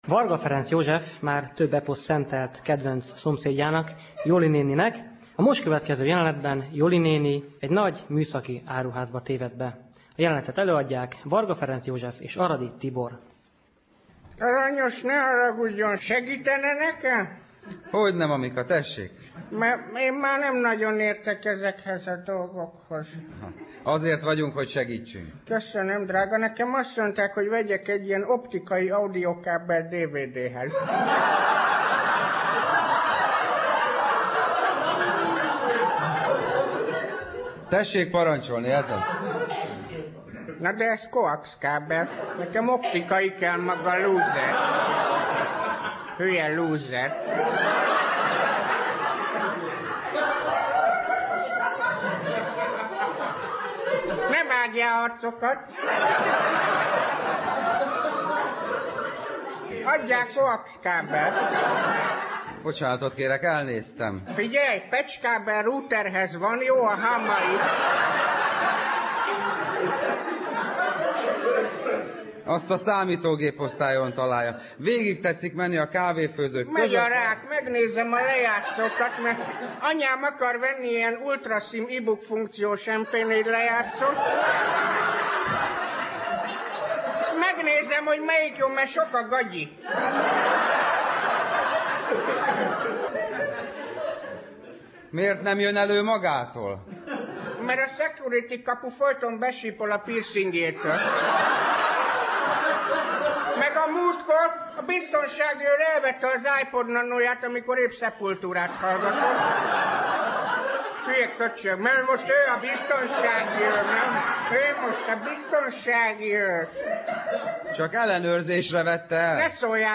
Az alábbi felvételek a Magyar Rádióban készültek.